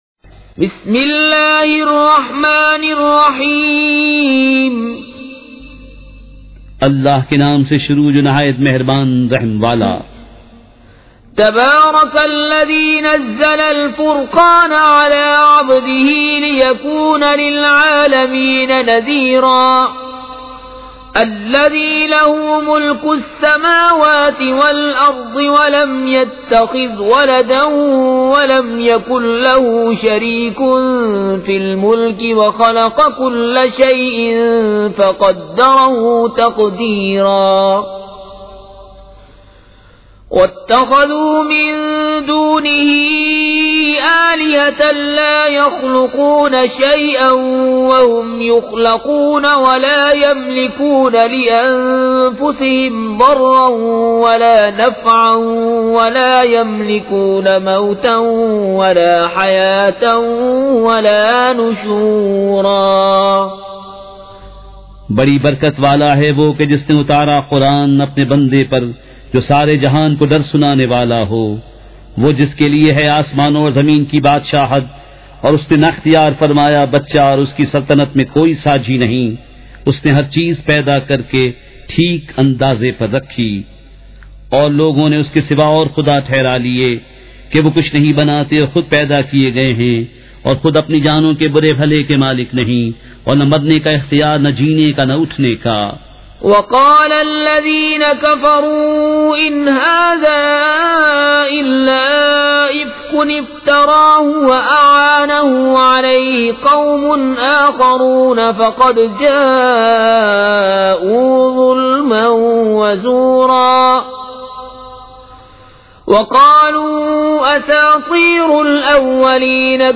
سورۃ الفرقان مع ترجمہ کنزالایمان ZiaeTaiba Audio میڈیا کی معلومات نام سورۃ الفرقان مع ترجمہ کنزالایمان موضوع تلاوت آواز دیگر زبان عربی کل نتائج 2123 قسم آڈیو ڈاؤن لوڈ MP 3 ڈاؤن لوڈ MP 4 متعلقہ تجویزوآراء